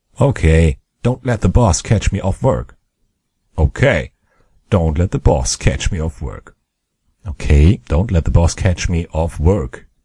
Tag: 语音 英语 文字 声音 要求 口语